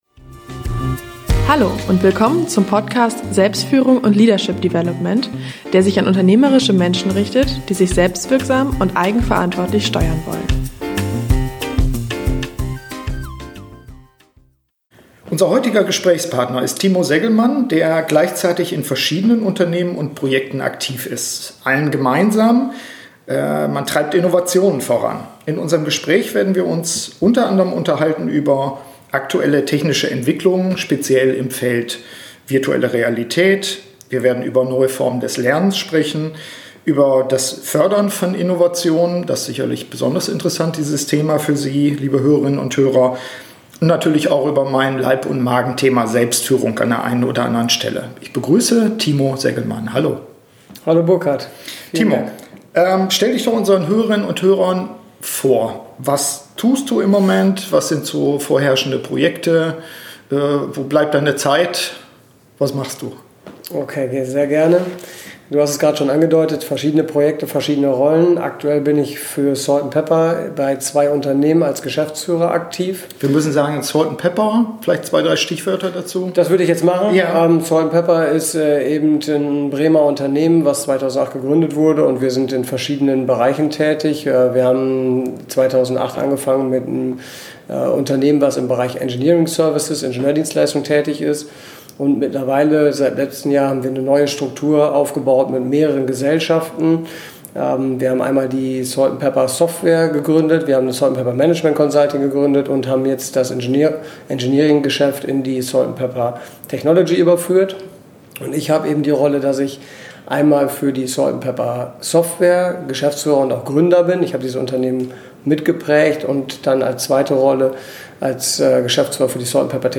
In unserem Gespräch unterhalten wir uns u.a. über aktuelle technische Entwicklungen, speziell im Feld Virtuelle Realität, über neue Formen des Lernens, über das Fördern von Innovationen und über die eigene Selbstführung und wie er seine „Batterien“ wieder auflädt.